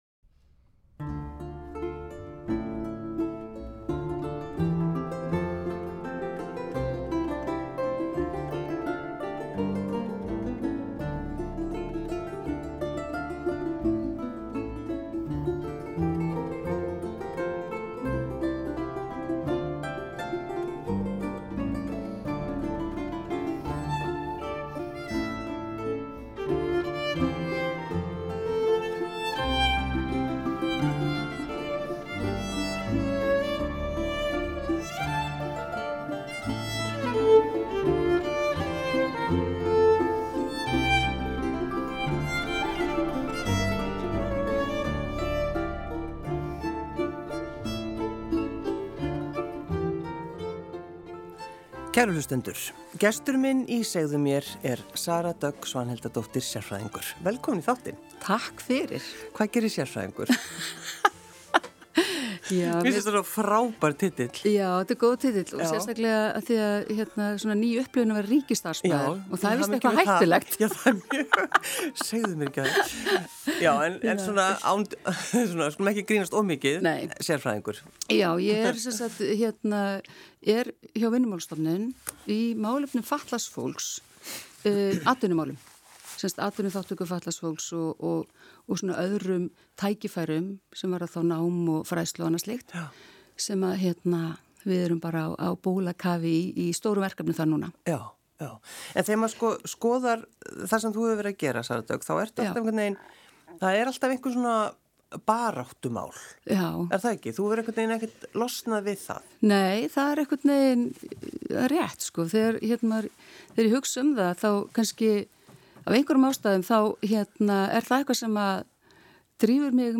Gestir úr öllum kimum þjóðfélagsins deila sögu sinni með hlustendum.